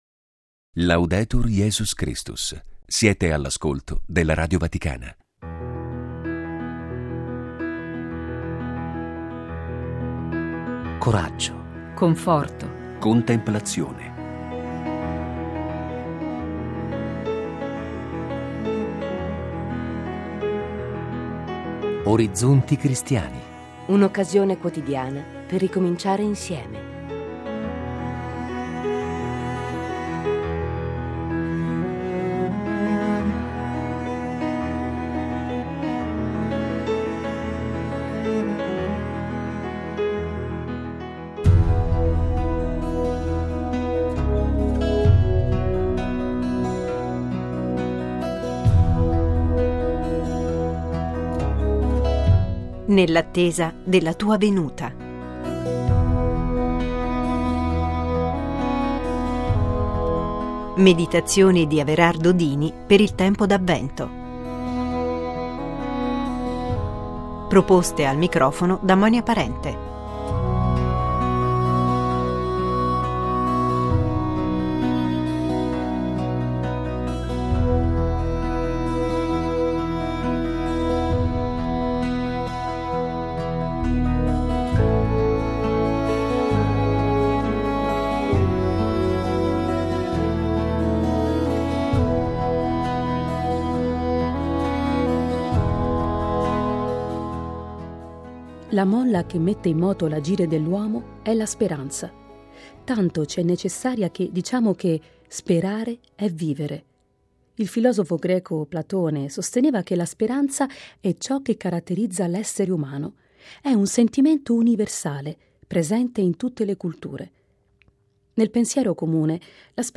Meditazioni per il Tempo d'Avvento